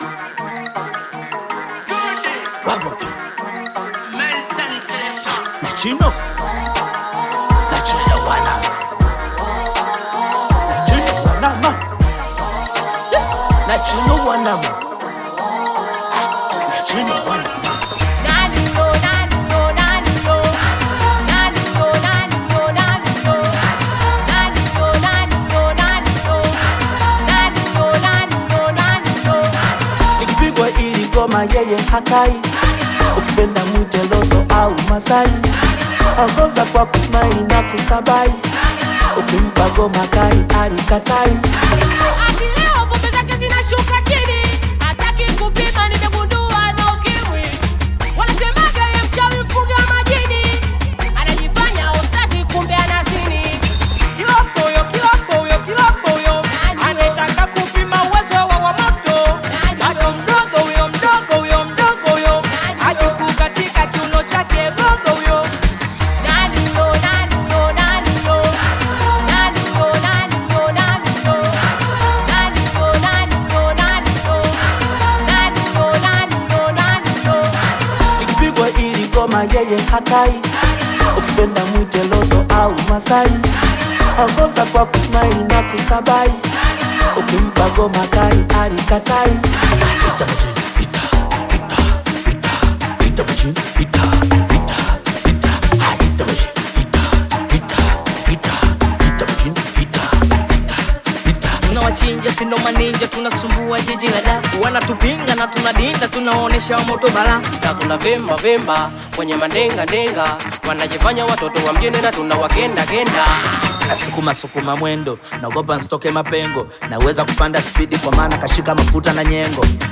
Bongo Flava
Tanzanian Bongo Flava artists
Bongo Flava song